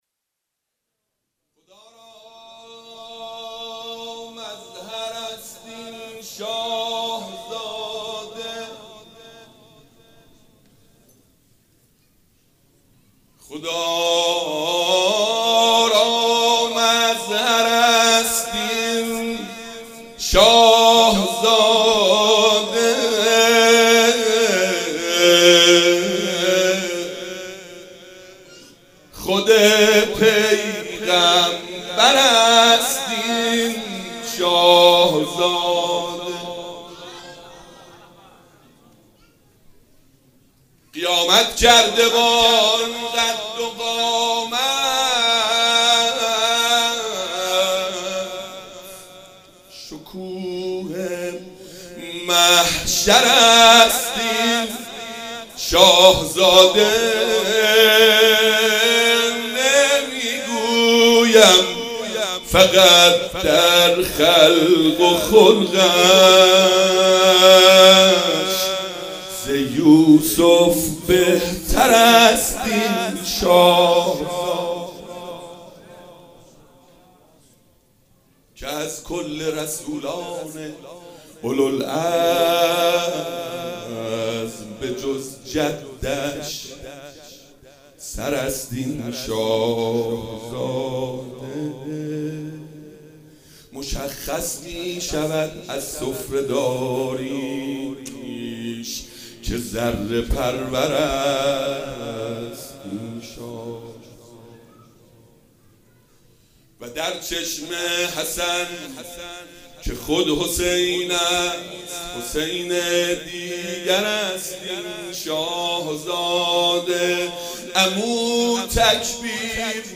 مناسبت : ولادت حضرت علی‌اکبر علیه‌السلام
قالب : مدح